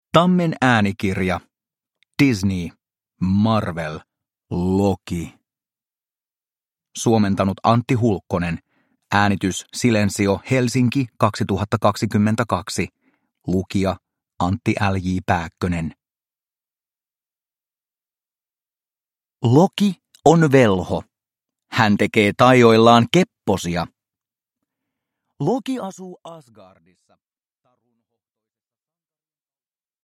Marvel. Loki – Ljudbok – Laddas ner